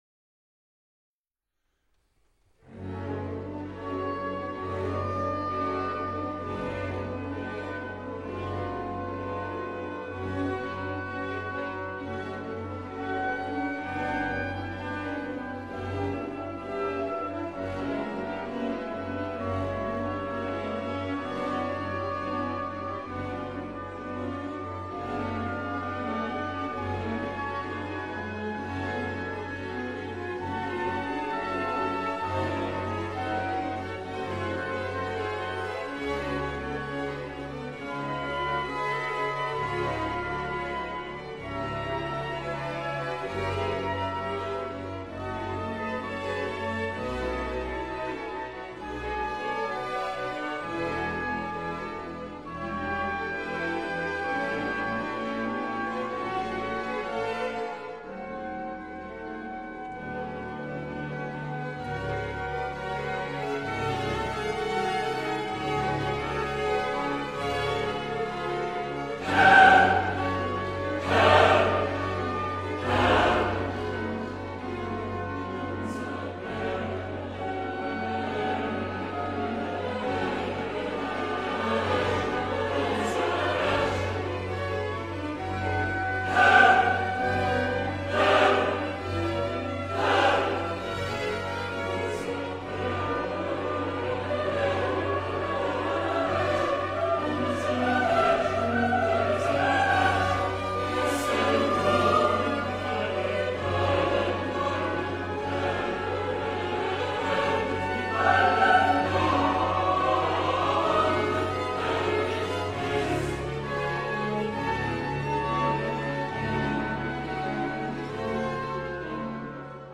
I Coro (mp3),  Qui i MIDI di tutti i movimenti
Bach_Johannes-Passion_Harnoncourt_CORO_nr.1.mp3